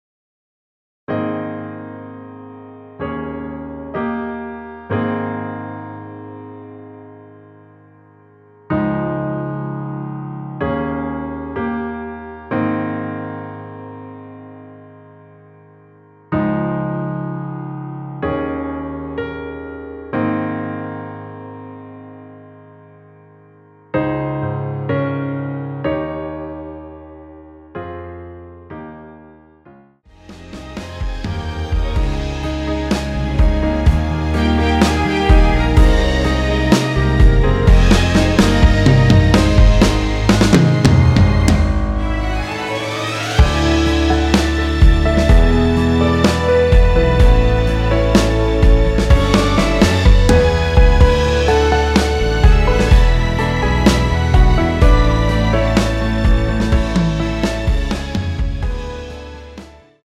전주 없이 시작하는 곡이라 전주 만들어 놓았습니다.(미리듣기 확인)
원키에서(-1)내린 MR입니다.
Ab
앞부분30초, 뒷부분30초씩 편집해서 올려 드리고 있습니다.
중간에 음이 끈어지고 다시 나오는 이유는